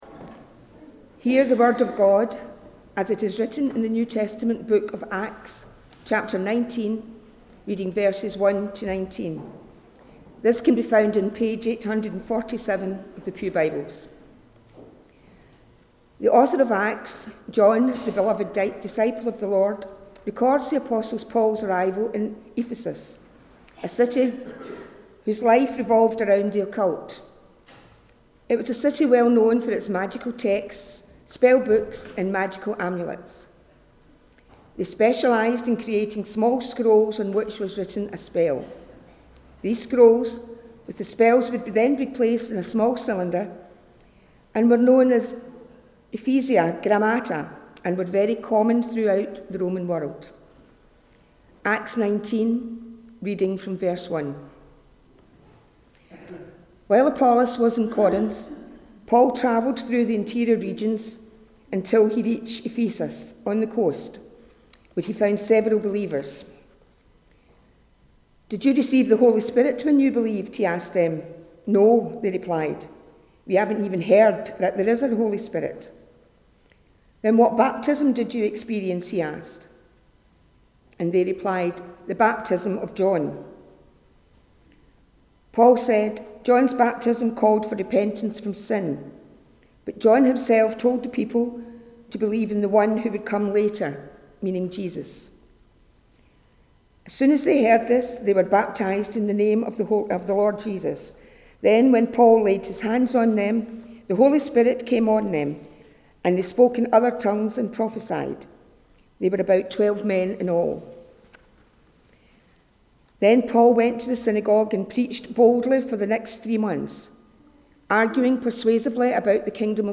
The Reading prior to the Sermon is Acts 19: 1-19